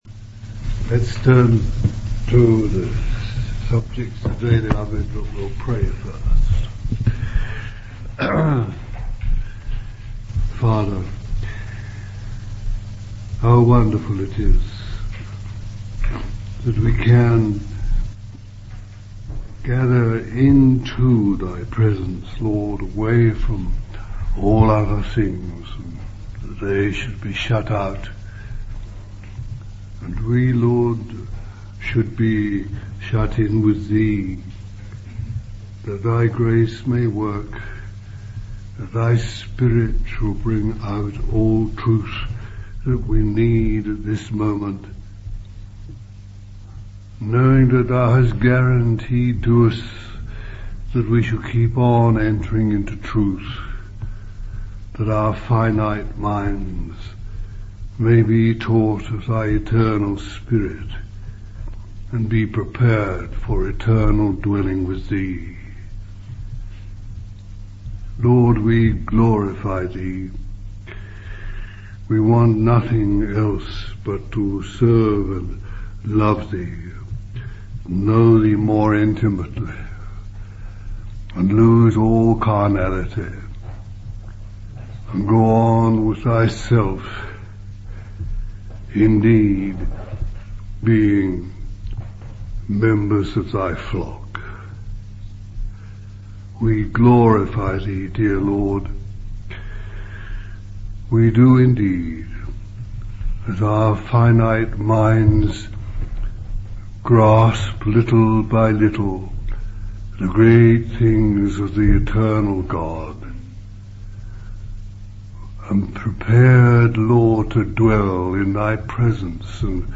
In this sermon, the speaker emphasizes the importance of the Bible and its significance in the lives of believers.